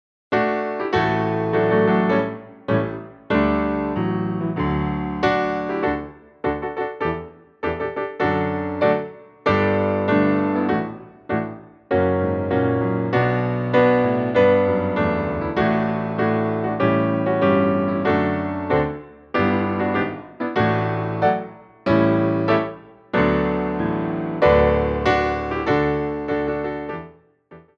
Grand Battements
4/4 (8x8)